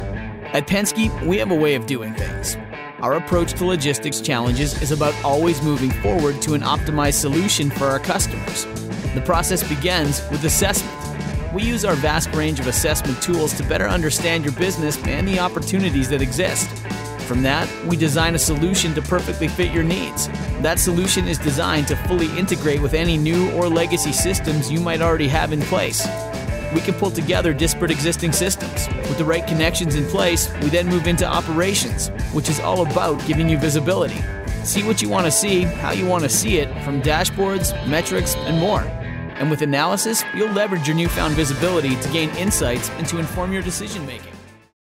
Englisch (Kanadisch)
Unternehmensvideos
Erklärvideos
-Professionelles Studio mit Tonkabine